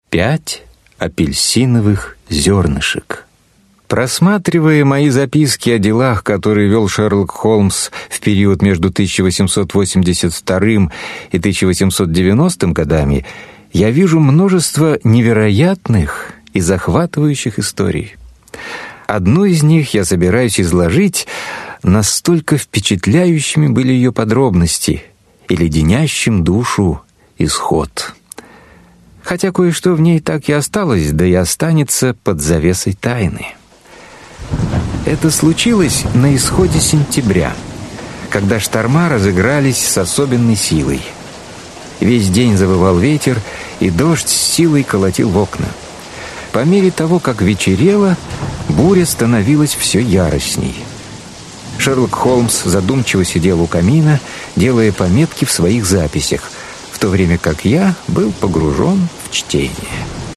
Аудиокнига Тайна Боскомской долины. 4 рассказа о Шерлоке Холмсе в аудиоспектаклях | Библиотека аудиокниг